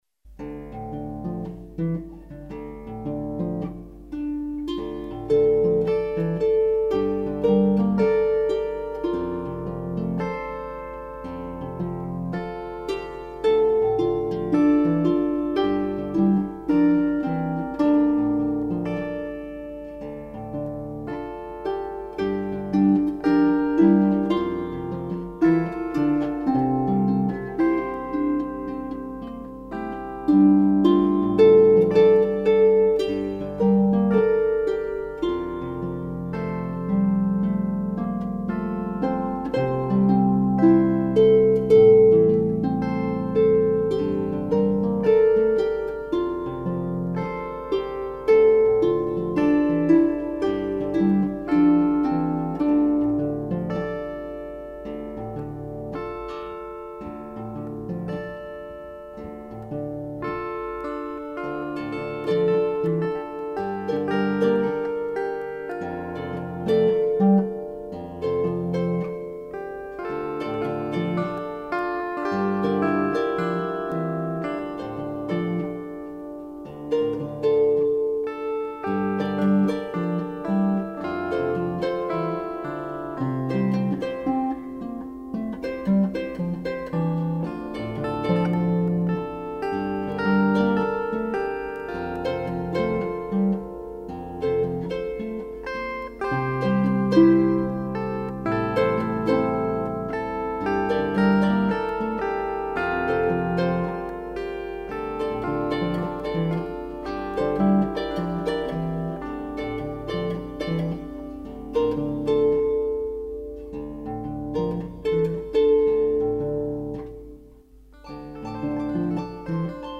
gut-strung harp & electric harp
Notes: Just in time for Christmas, a real basement tape!